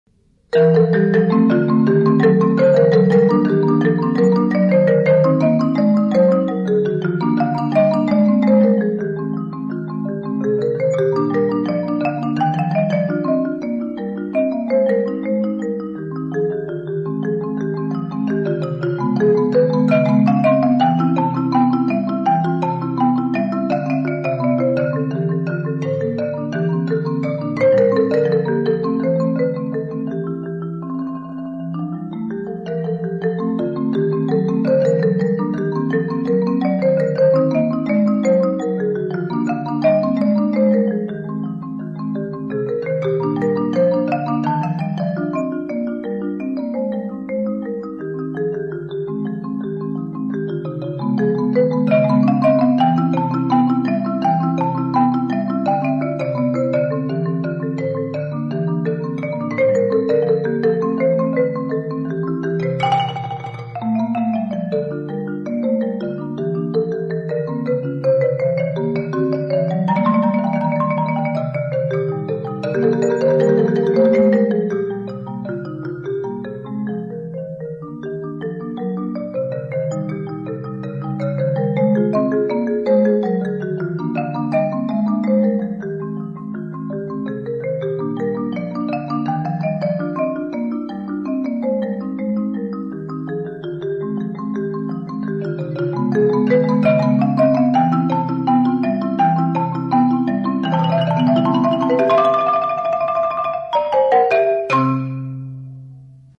Four duets for two players on one 4-1/3 octave marimba.